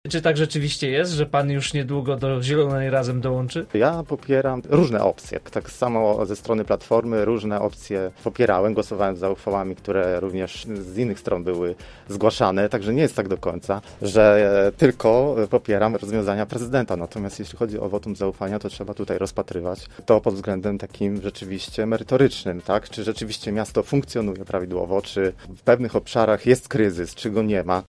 Mnie interesują konkretne zadania – mówił pytany na naszej antenie o ewentualny transfer:
Przewodniczący komisji do spraw konsultacji społecznych i budżetu obywatelskiego był gościem Rozmowy po 9.